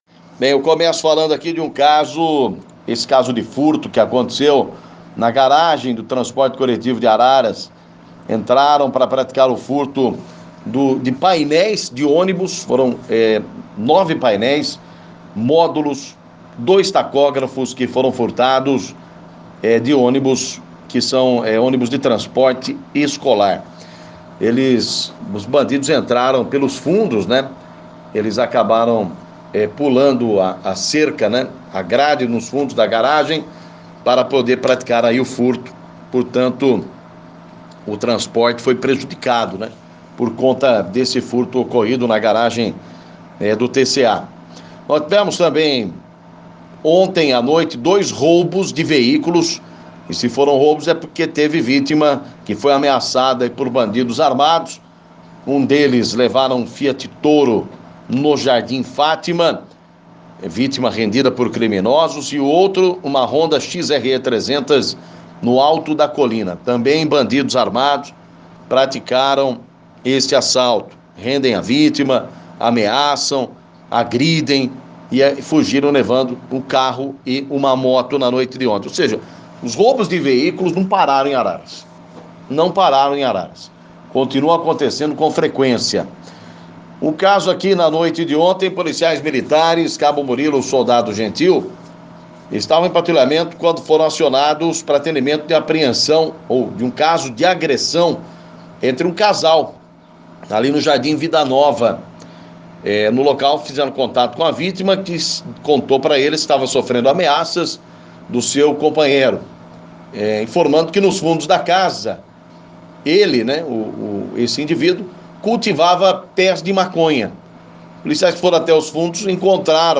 Destaque Polícia